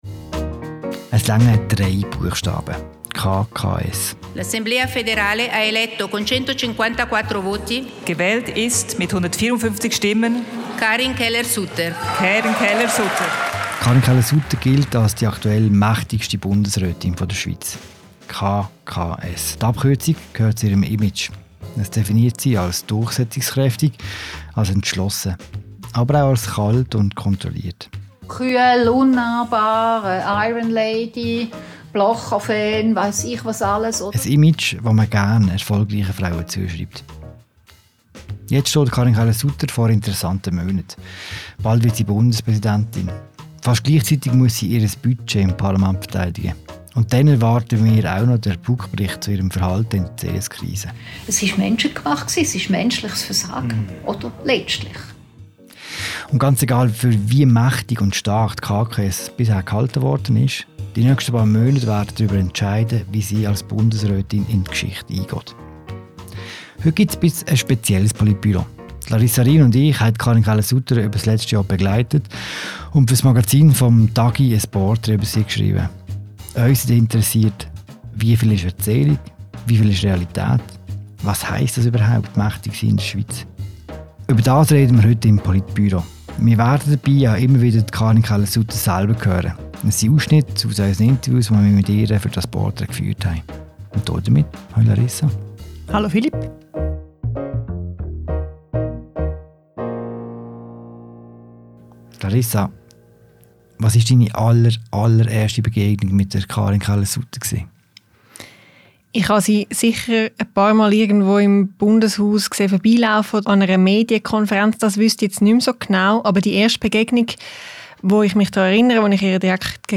Im Podcast sprechen sie über ihrer Eindrücke – und lassen Karin Keller-Sutter zu Wort kommen.